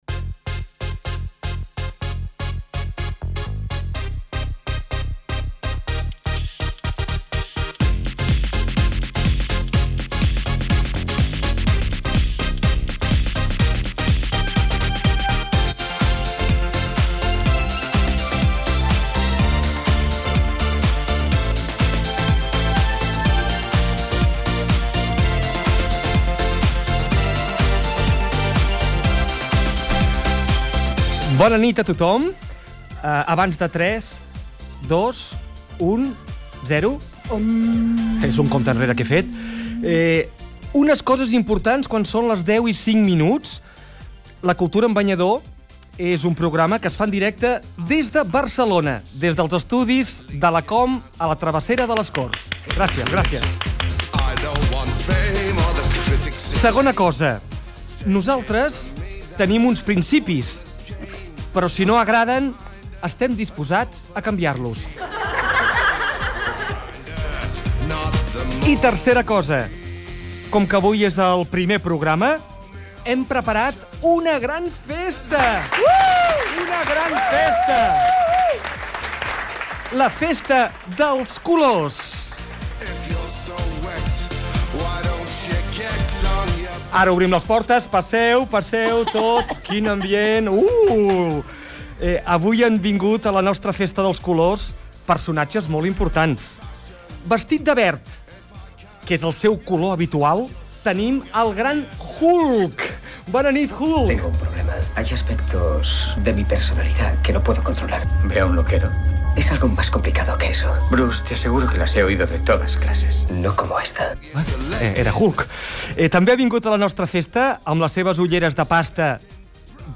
FM
Fragment extret de l'arxiu sonor de COM Ràdio.